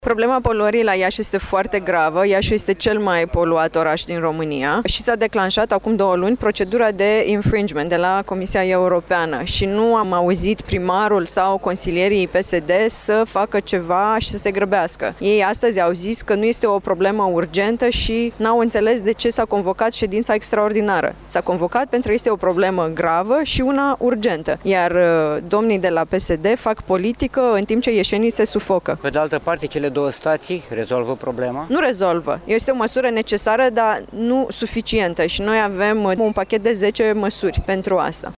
Parlamentarul USR de Iaşi,  Cosette Chichirău,  a delcarat că votul de astăzi din Consiliul Local nu onorează Iaşul şi a recunoscut că doar montarea unor staţii de filtare nu reprezintă o măsură suficientă, însă este una necesară: